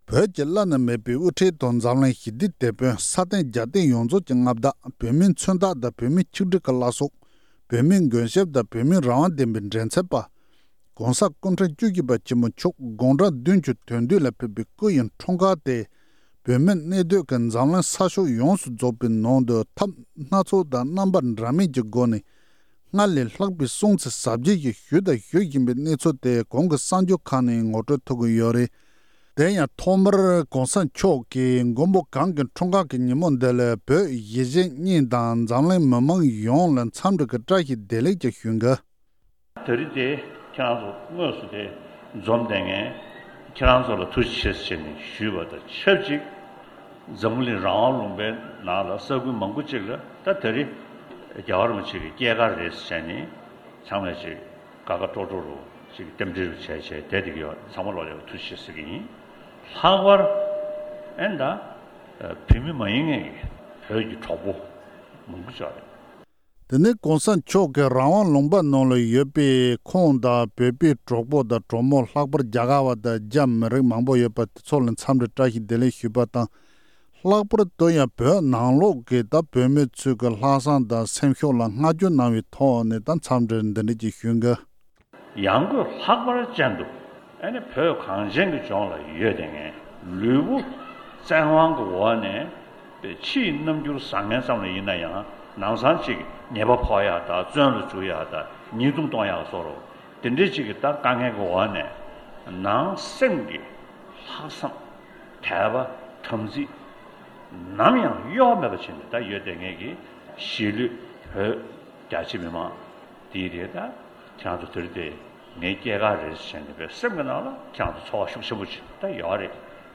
༸གོང་ས་མཆོག་དགུང་གྲངས་༧༧ལ་ཕེབས་པའི་སྐུའི་འཁྲུངས་སྐར་གྱི་ཉིན་མོར་བོད་ནས་གཞས་པ་ཁག་གིས་ཕུལ་བའི་བསྟོད་གླུ་གསན་རོགས་ཞུ།